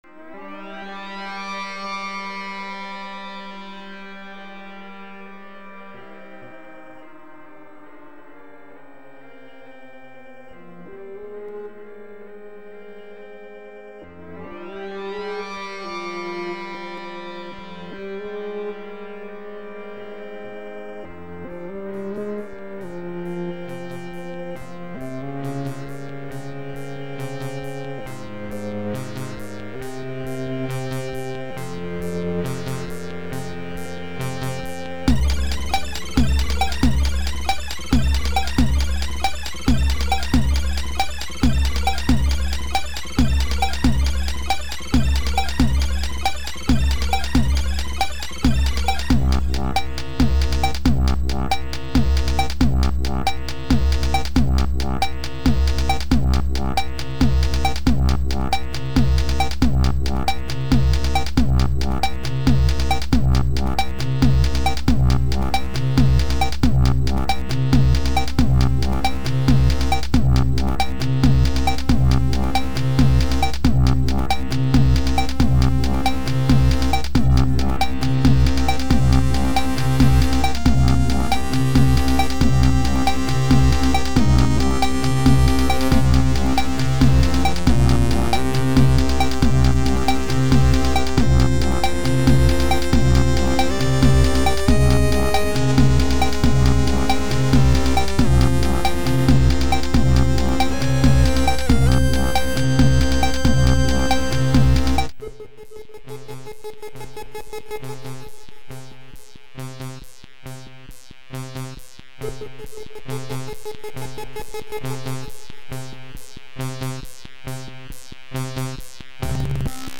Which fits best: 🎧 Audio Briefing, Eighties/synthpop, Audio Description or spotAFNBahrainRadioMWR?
Eighties/synthpop